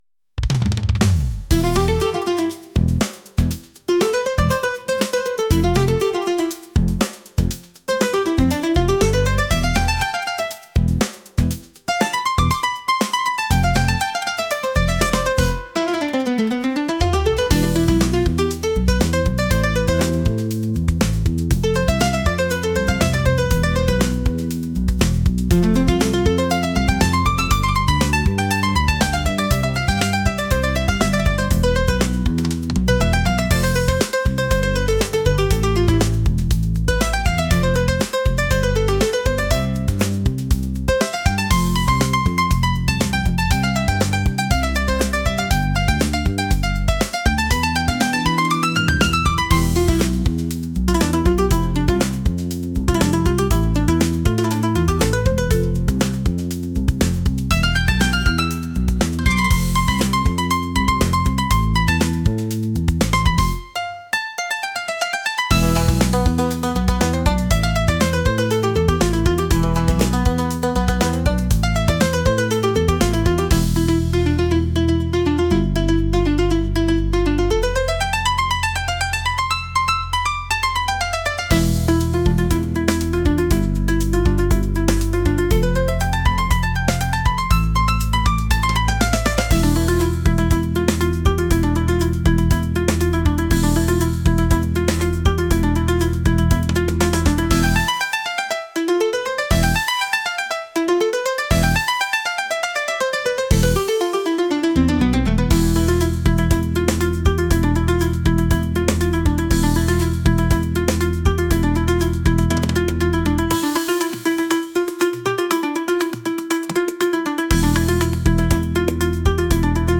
fusion | world